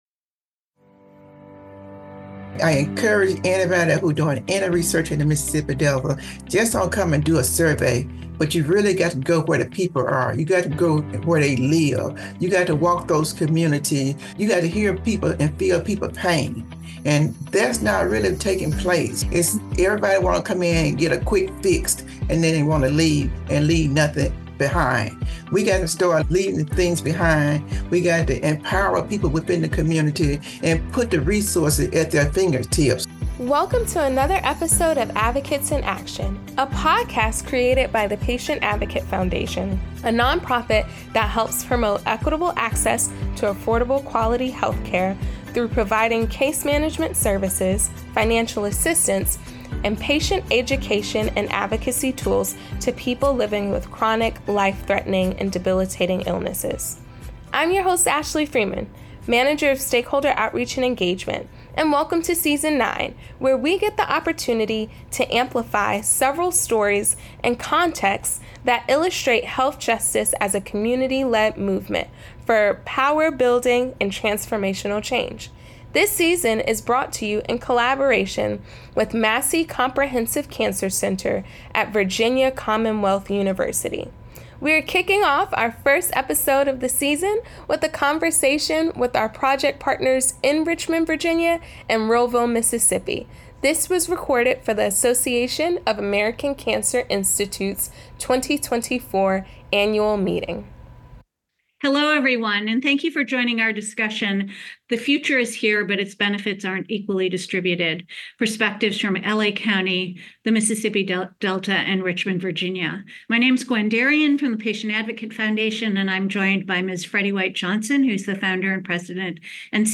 But have these breakthroughs reached the communities that need them most? In this powerful discussion